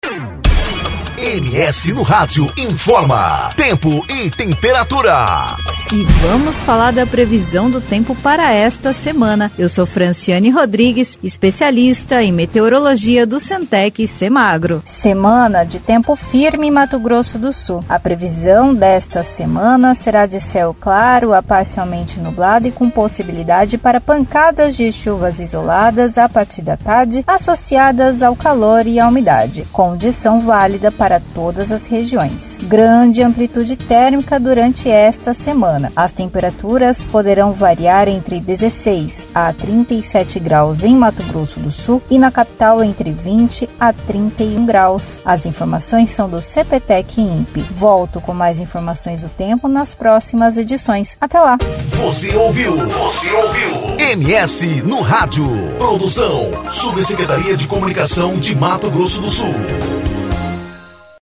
Previsão do Tempo: Tempo firme; temperaturas variando entre a mínima de 16 e a máxima de 36 graus